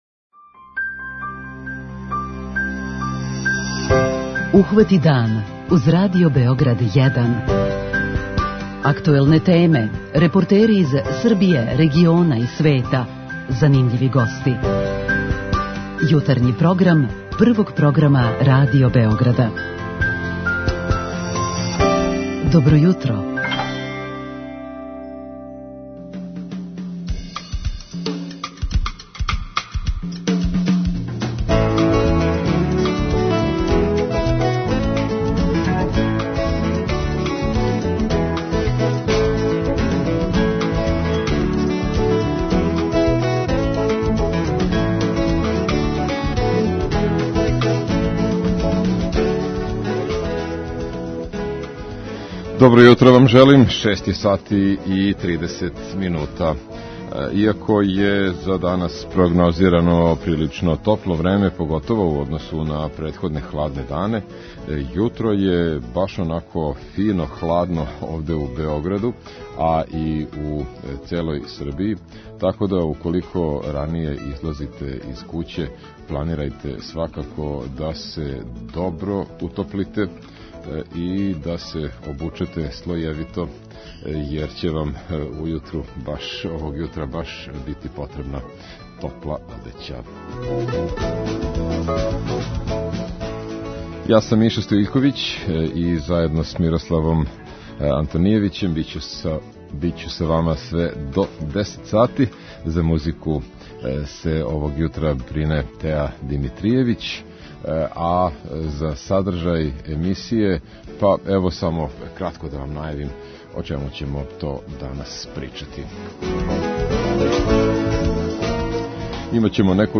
Претходно ћемо и са слушаоцима, у нашој редовној рубрици 'Питање јутра', причати о томе како они виде највеће изазове за Србију у области економије.